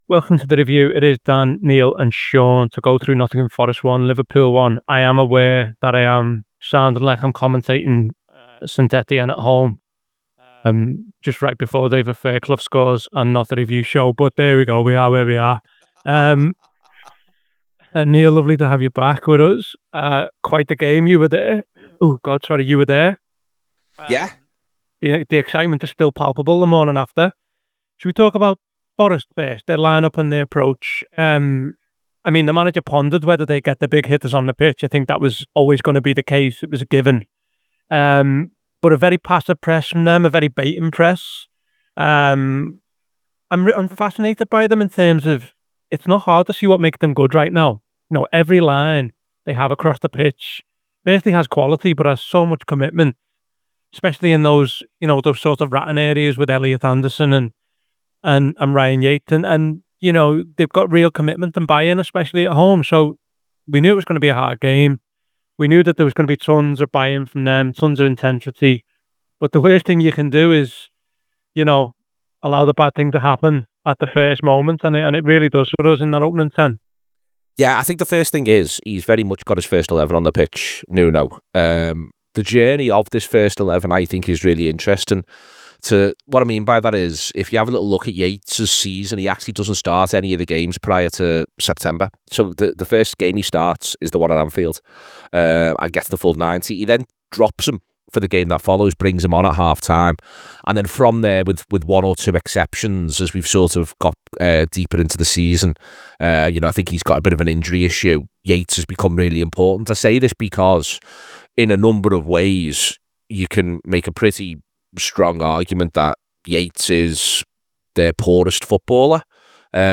Below is a clip from the show- subscribe for more review chat around Nottingham Forest 1 Liverpool 1…